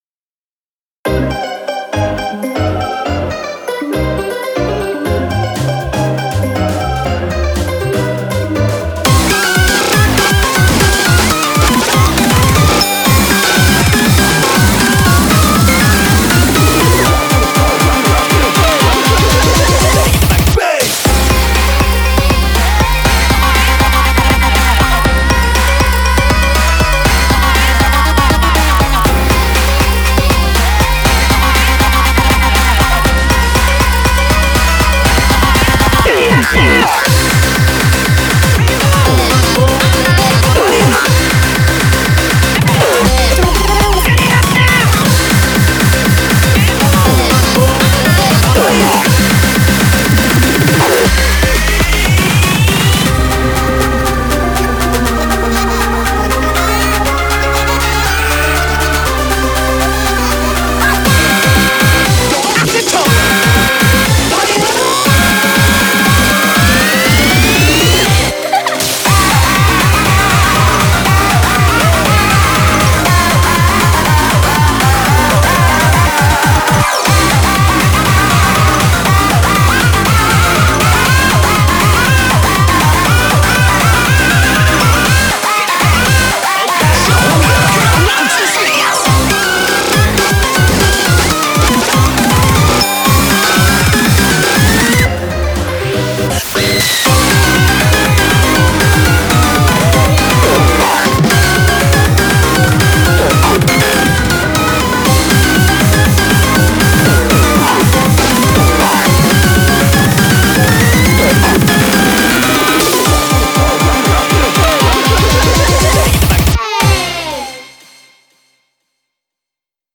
BPM120-240
Audio QualityPerfect (High Quality)
Beware of the slowdowns.